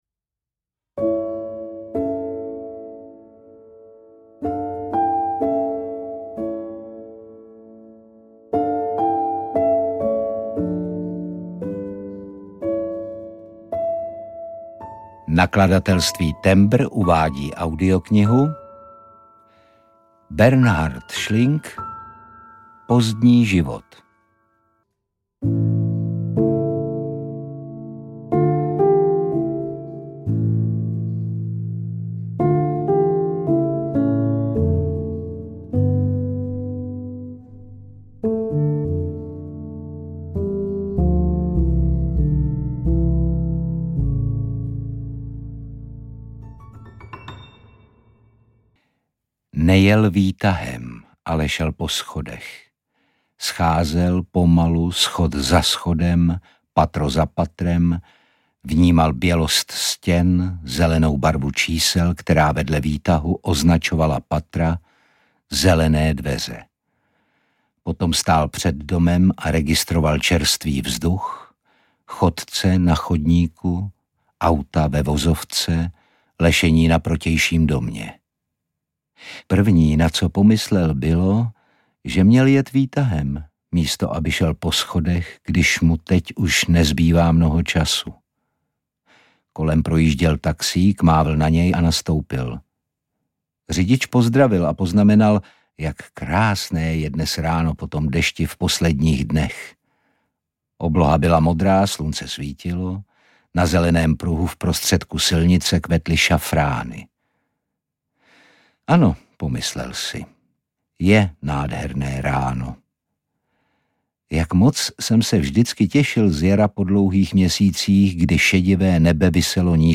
Pozdní život audiokniha
Ukázka z knihy
• InterpretOtakar Brousek ml.